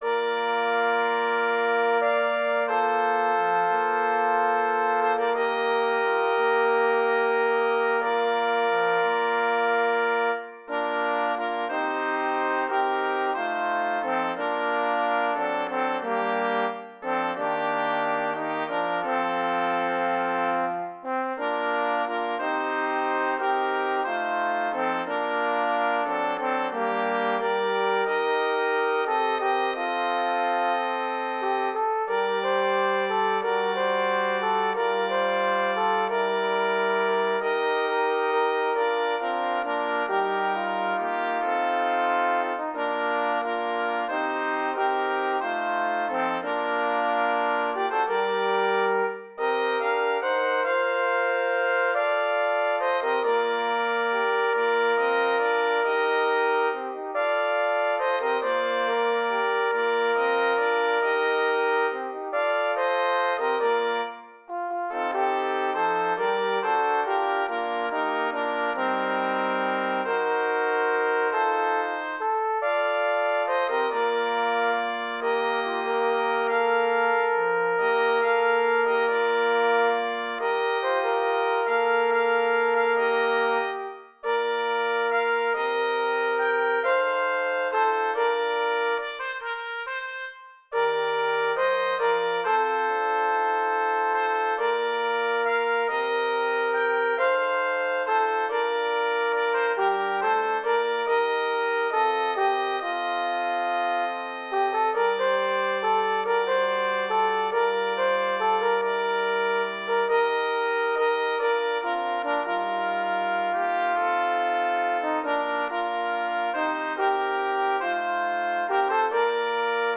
Digital audio track (no vocals)